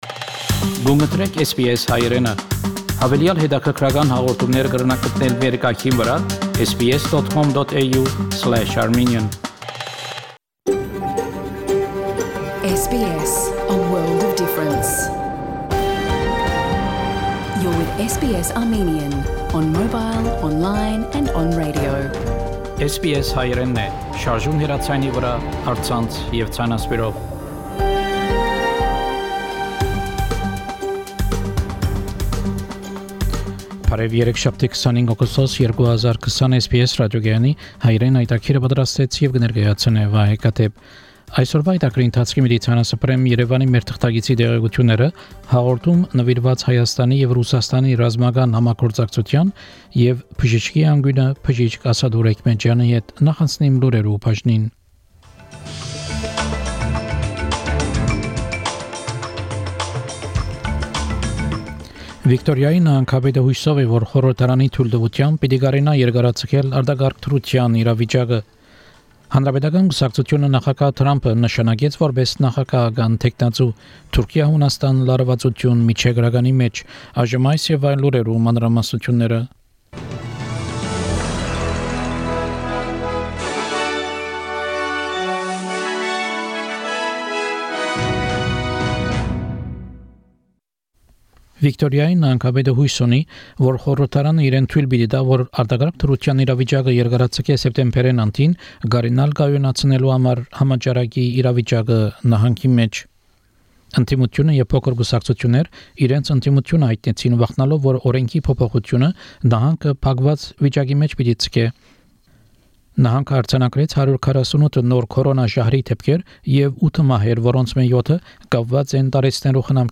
SBS Armenian news bulletin – 25 August 2020
SBS Armenian news bulletin from 25 August 2020 program.